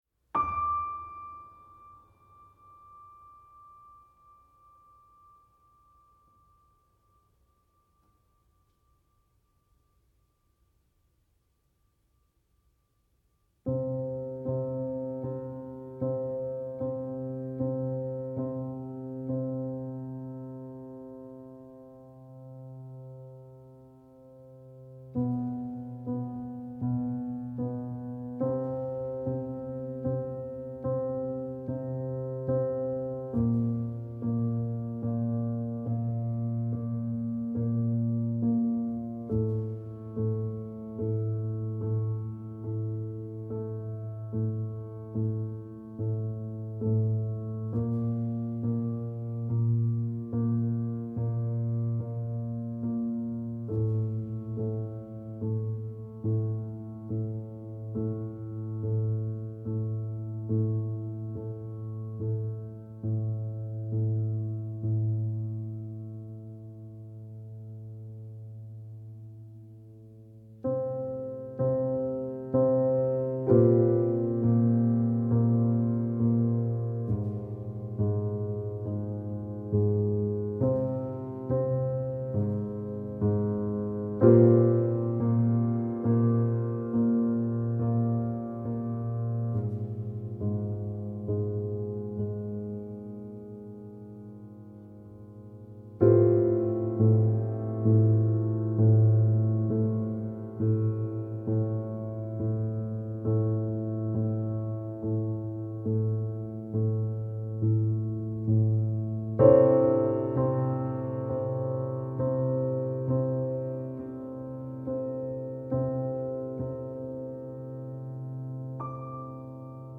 a piano work that transforms time perception.